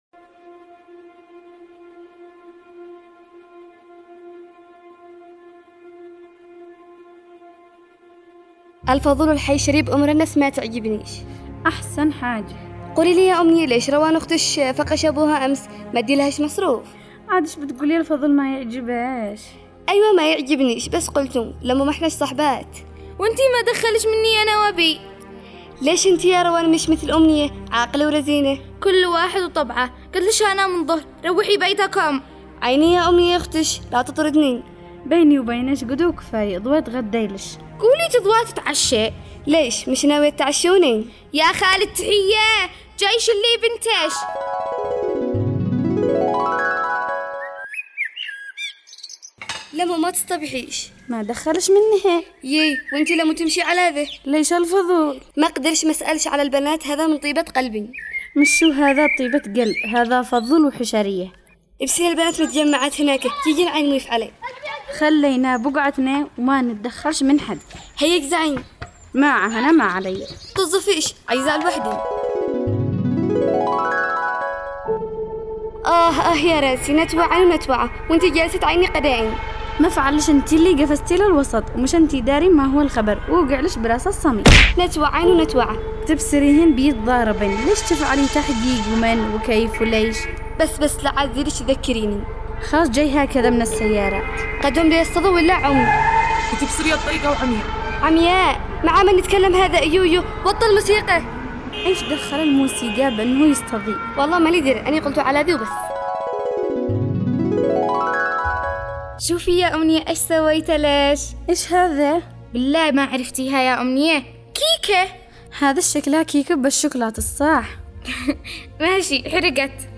دراما رمضانية
دراما رمضان  تستمعون إليه عبر إذاعة صغارنا كل سبت الساعة 5:00عصراٌ